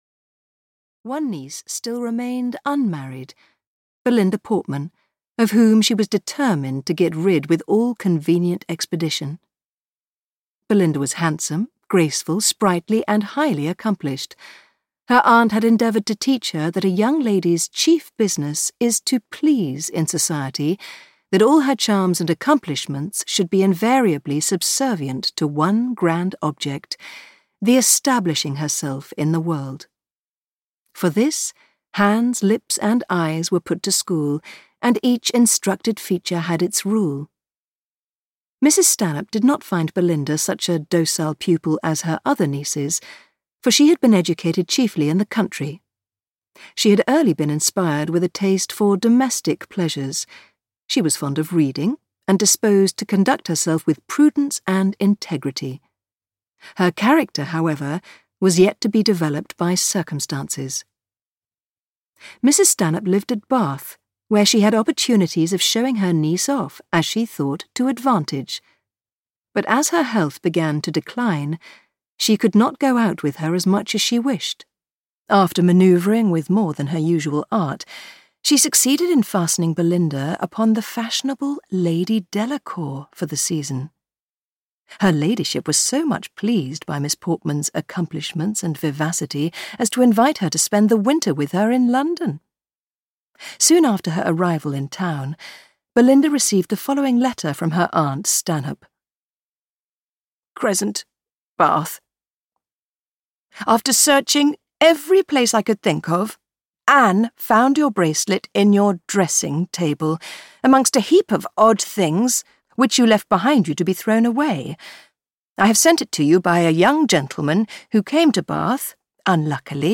Belinda (EN) audiokniha
Ukázka z knihy